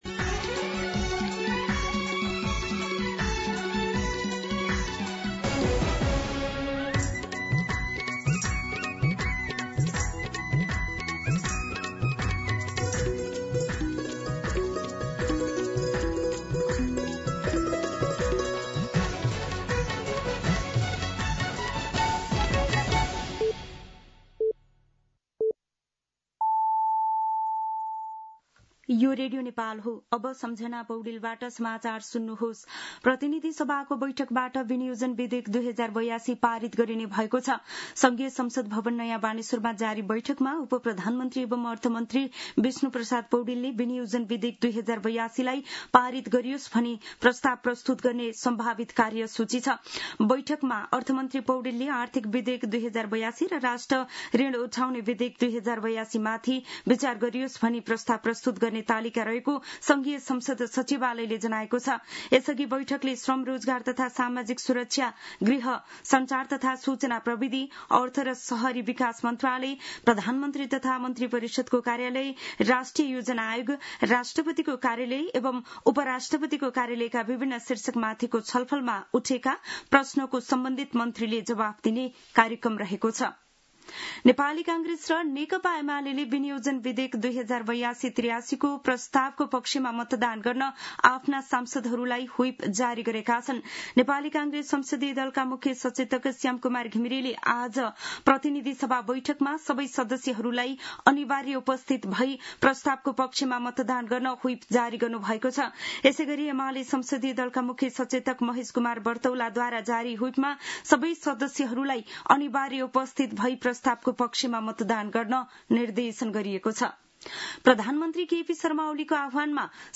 मध्यान्ह १२ बजेको नेपाली समाचार : १० असार , २०८२